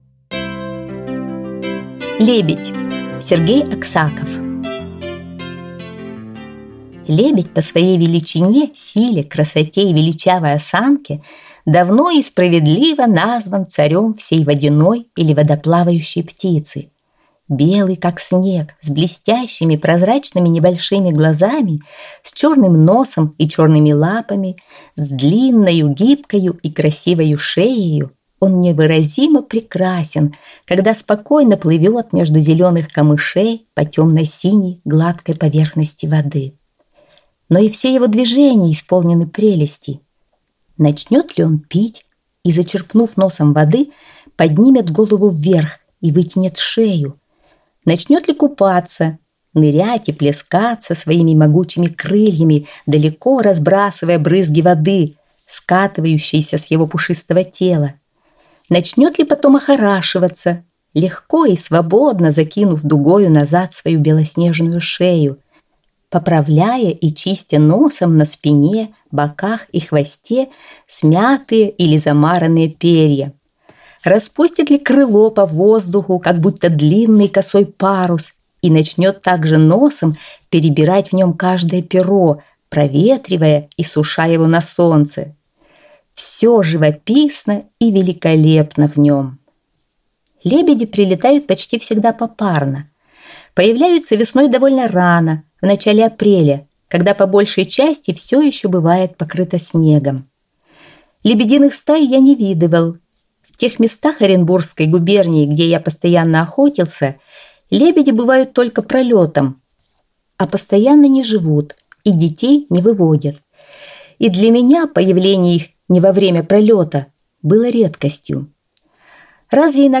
Аудиорассказ «Лебедь»